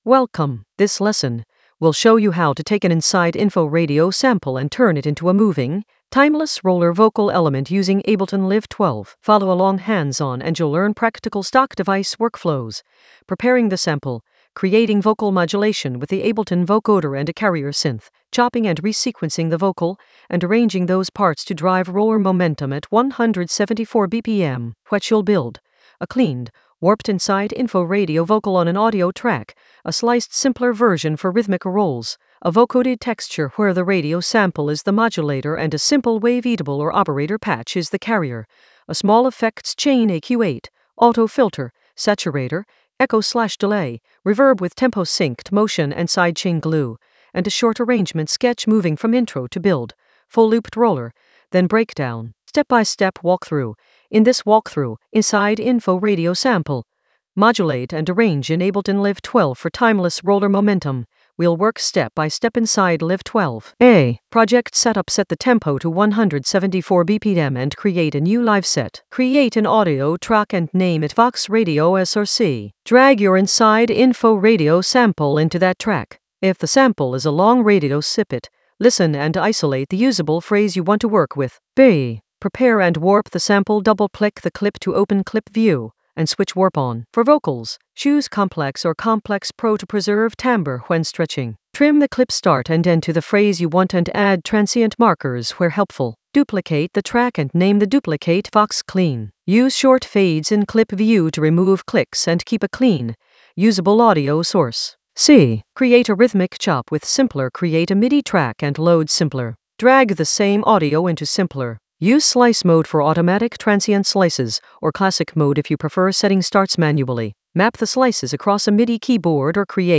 An AI-generated beginner Ableton lesson focused on InsideInfo radio sample: modulate and arrange in Ableton Live 12 for timeless roller momentum in the Vocals area of drum and bass production.
Narrated lesson audio
The voice track includes the tutorial plus extra teacher commentary.